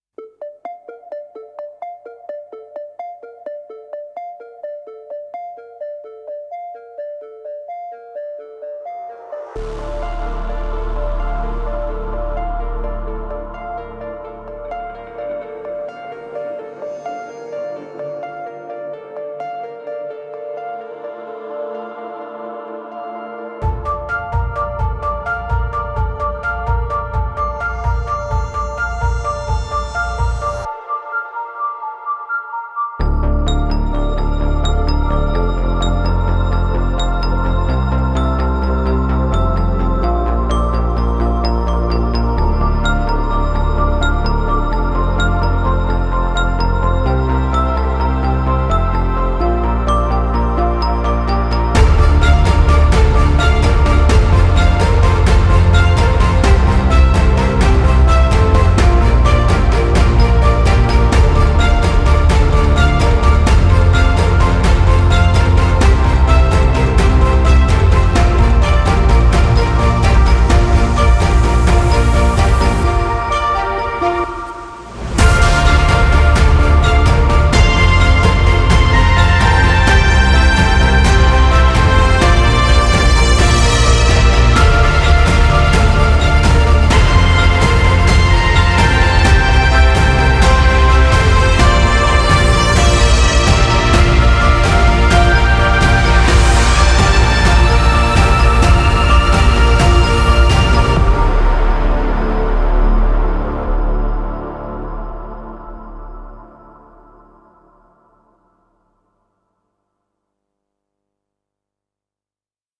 Popular Norwegian DJ and music producer